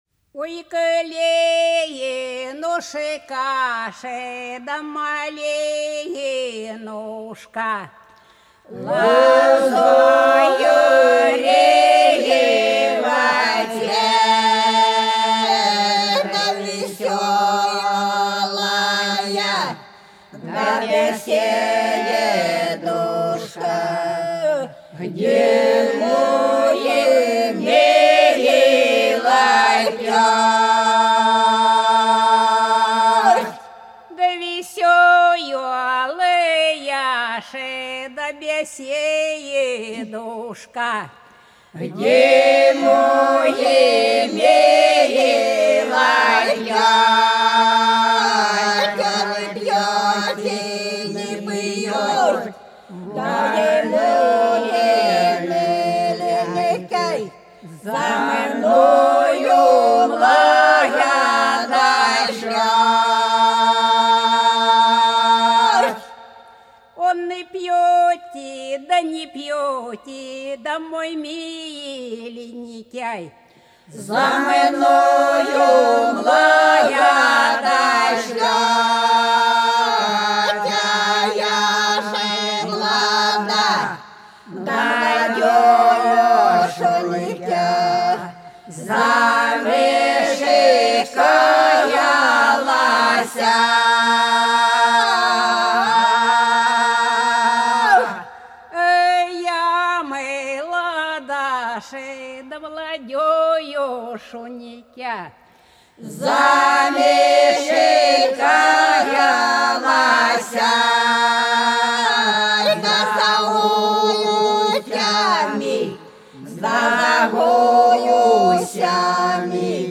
По-над садом, садом дорожка лежала Ой, калинушка, малинушка, лазоревый цвет - троицкая (с.Фощеватово, Белгородская область)